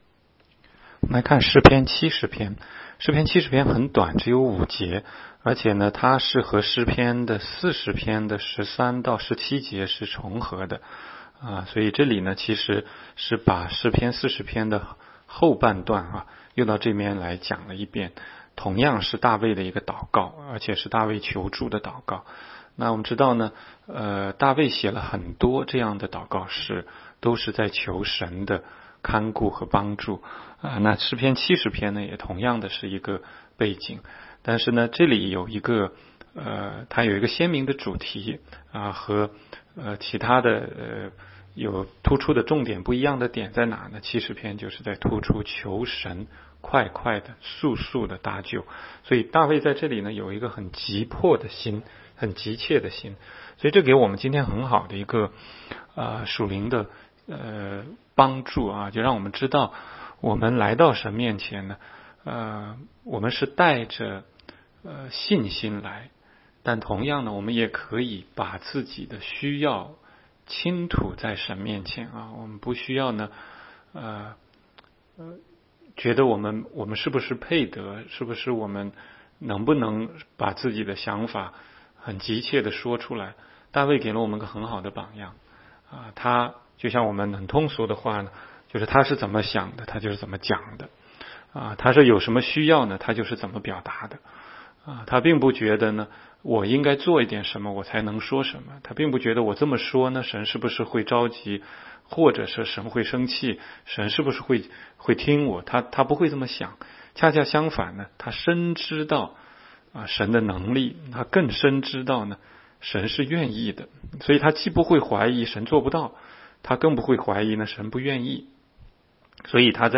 16街讲道录音 - 每日读经-《诗篇》70章